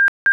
При переходе на батарею сигнал бипера двойной, оба сигнала длительностью 0.08сек и тон 1600Гц.